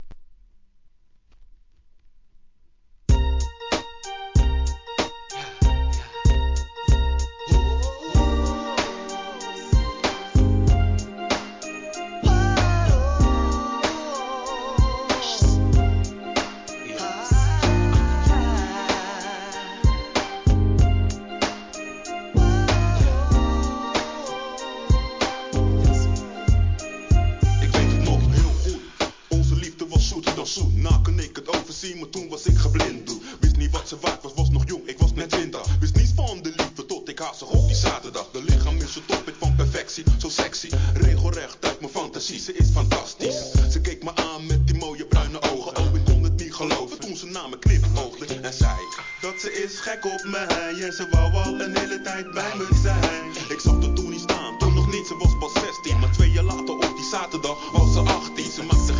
メローなトラックにコーラスを絡めるオーランド産HIP HOP!!